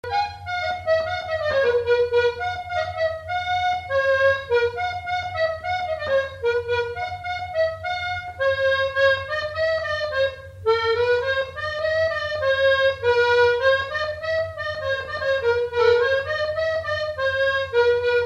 Note maraîchine
Couplets à danser
danse : branle : courante, maraîchine
Pièce musicale inédite